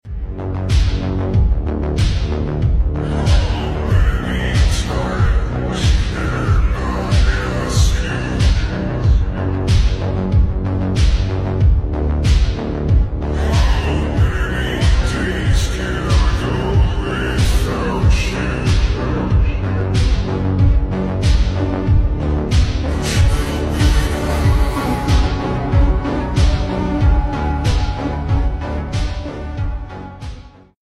Tactical Forces | Cinematic Military